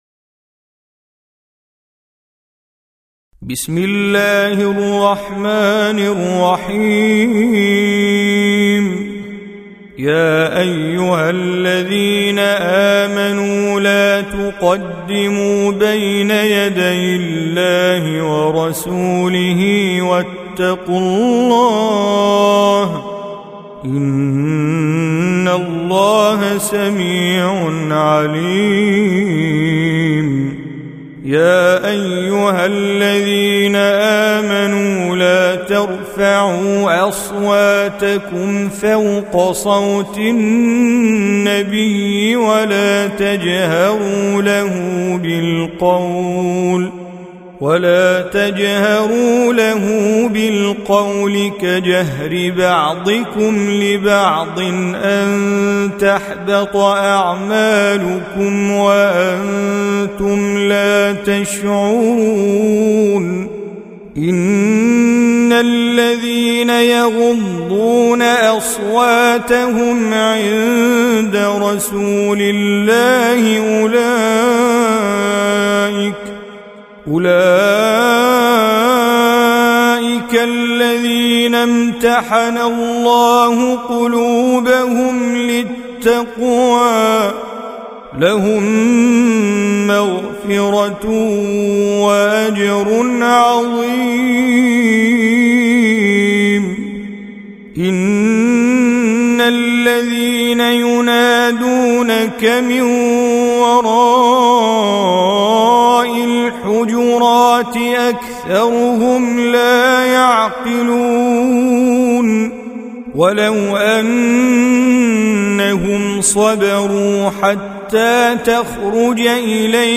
49. Surah Al-Hujur�t سورة الحجرات Audio Quran Tajweed Recitation
Surah Repeating تكرار السورة Download Surah حمّل السورة Reciting Mujawwadah Audio for 49.